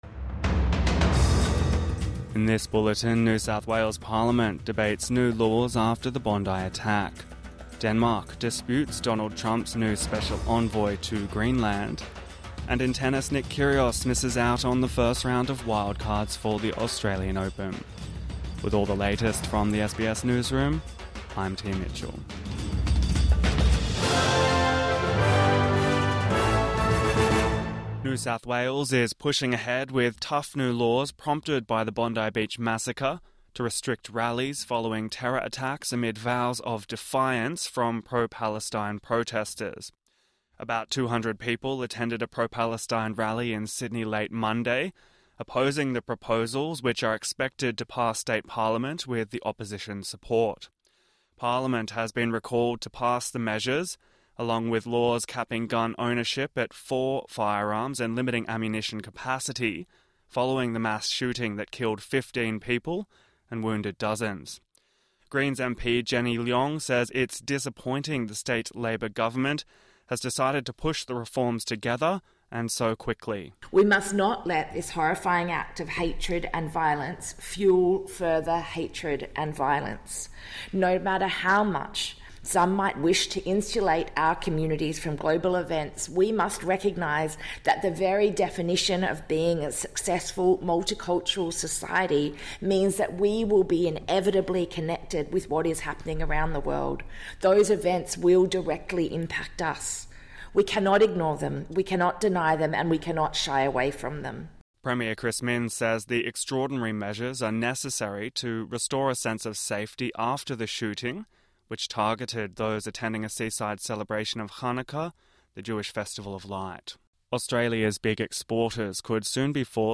Morning News Bulletin 23 December 2025